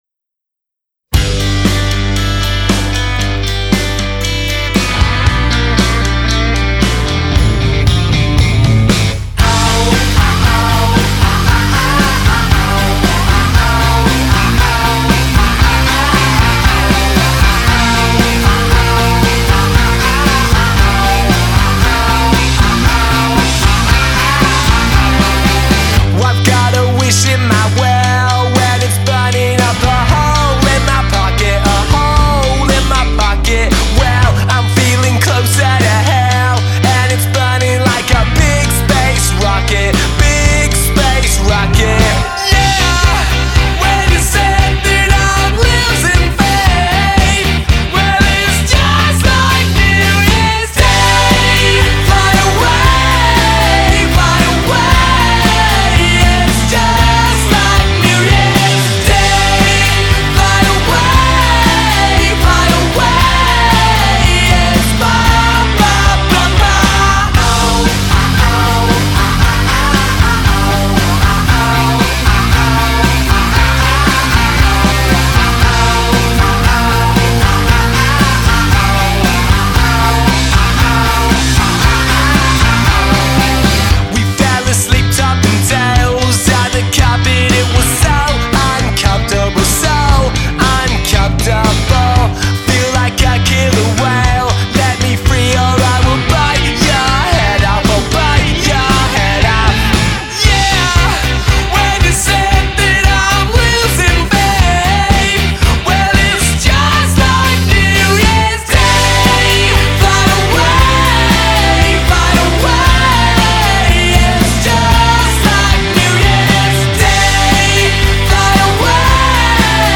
Super fun guitar pop rock.